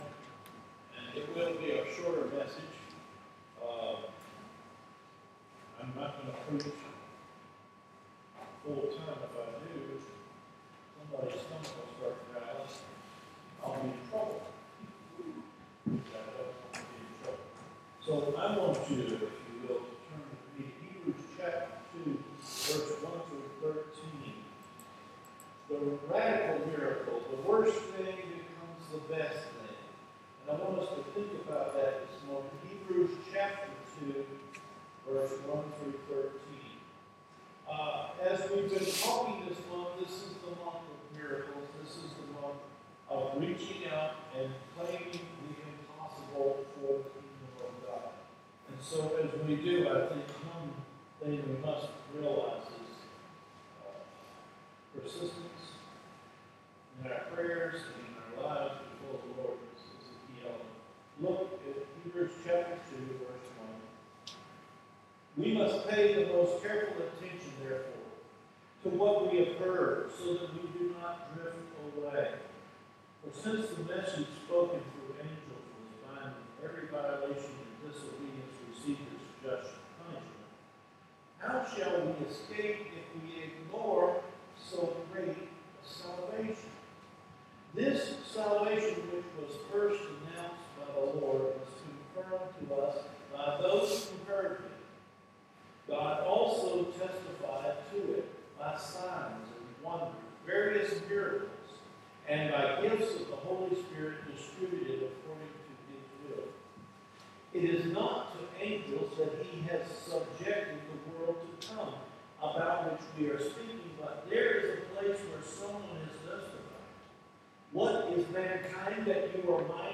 Recorded Sermons No comment AUGUST 22 SERMON